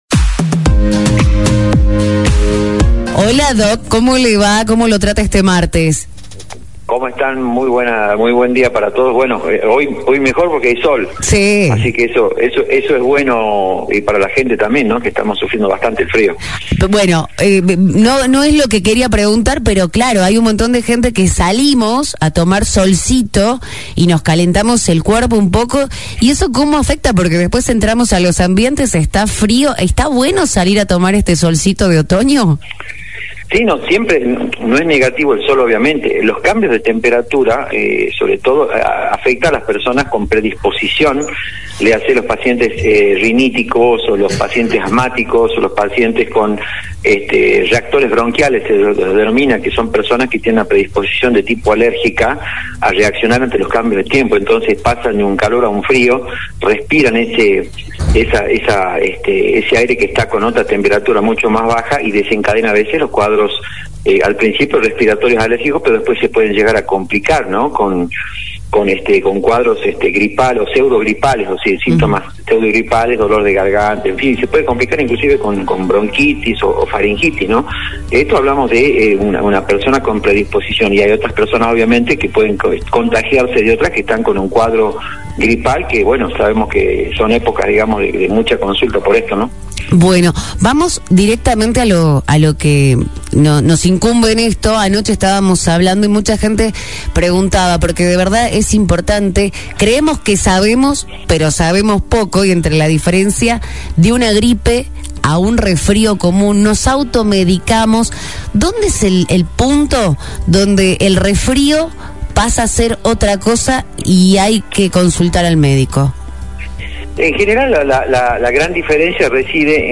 En el podcast de hoy charlamos con el médico infectólogo